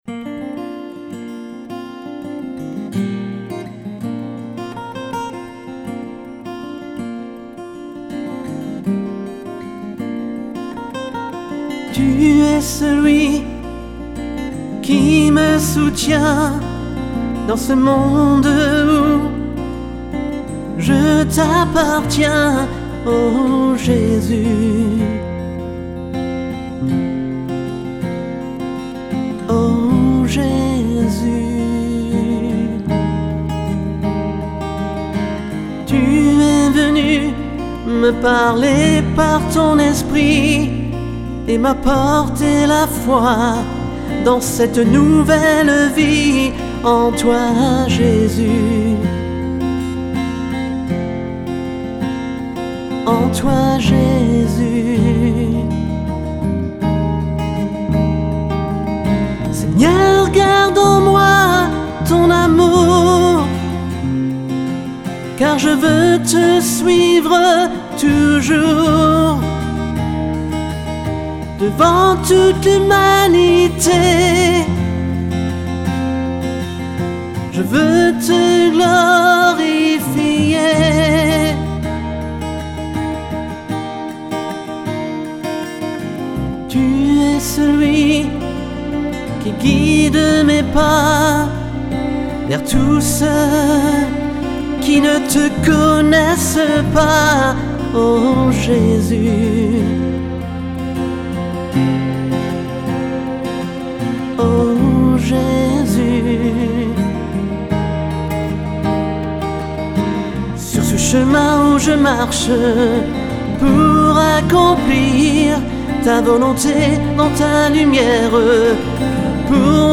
Voix et guitares
Basse